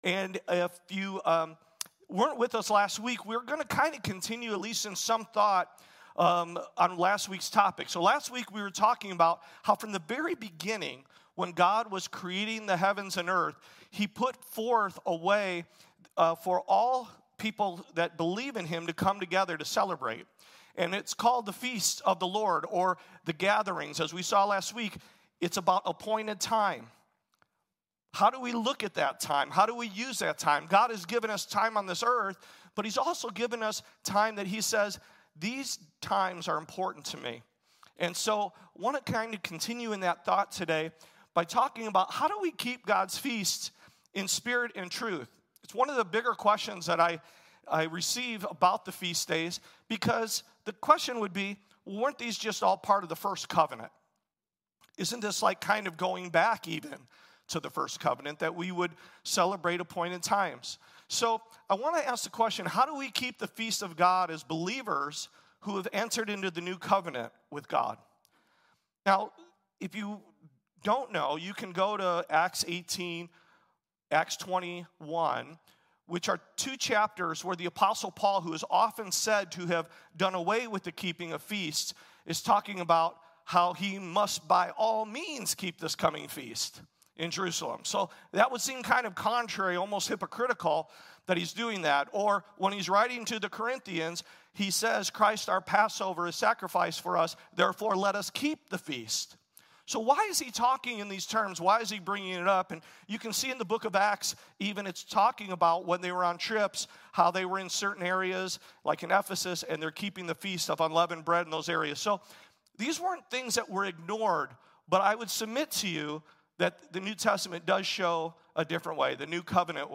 Keeping God’s Feasts in Spirit and Truth | Sabbath Christian Church | Rock Valley Christian Church